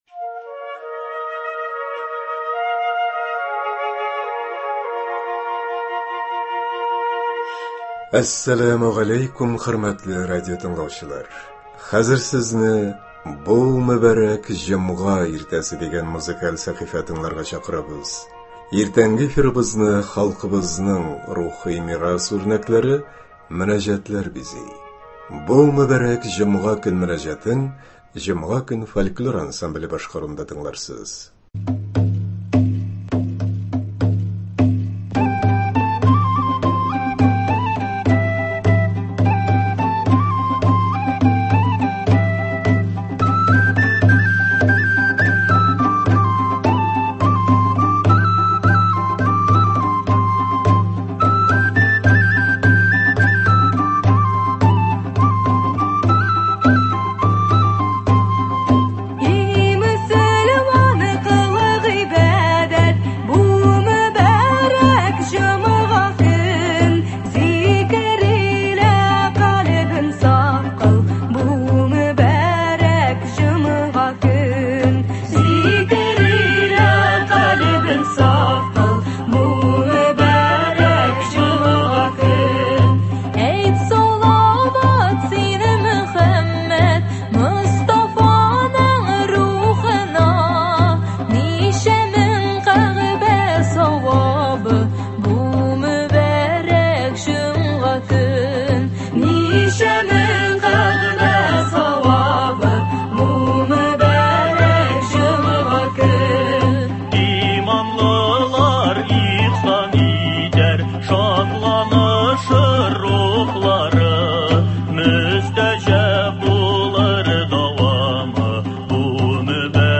Иртәнге эфирыбызны халкыбызның рухи мирас үрнәкләре – мөнәҗәтләр бизи.